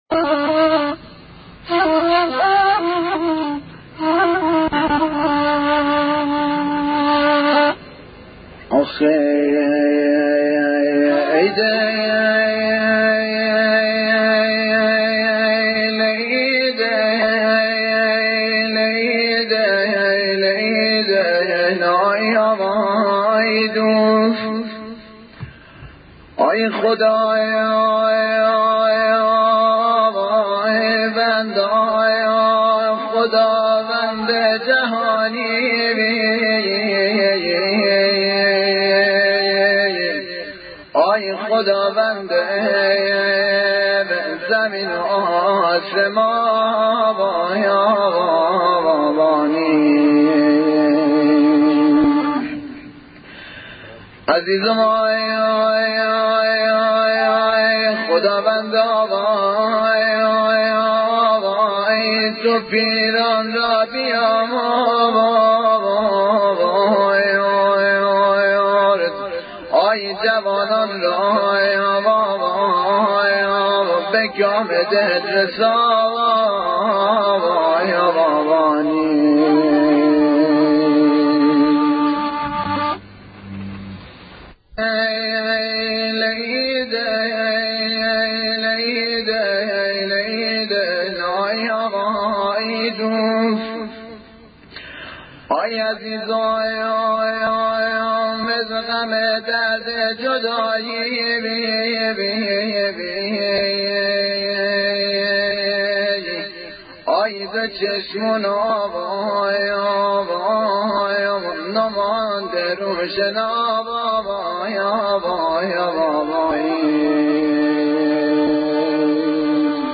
موزیک ترک , موزیک شاهسون